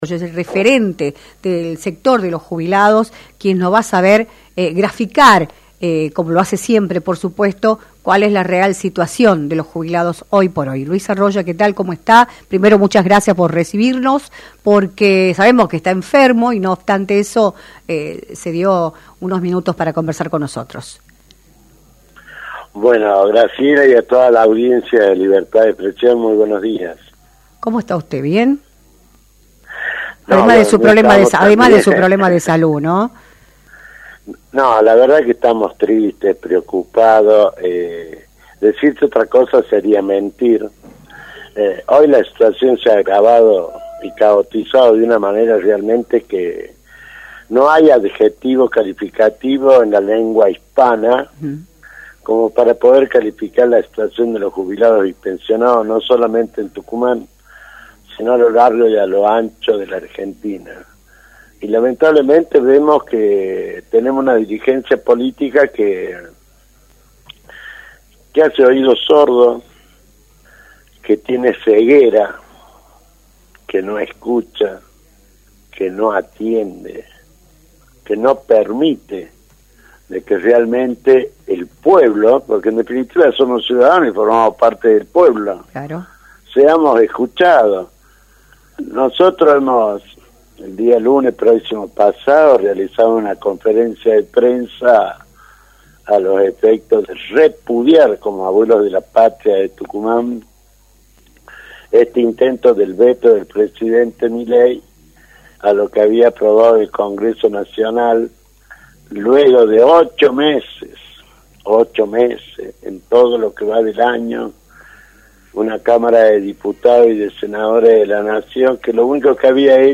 entrevista para “Libertad de Expresión” por la 106.9.